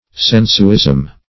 sensuism - definition of sensuism - synonyms, pronunciation, spelling from Free Dictionary Search Result for " sensuism" : The Collaborative International Dictionary of English v.0.48: Sensuism \Sen"su*ism\, n. Sensualism.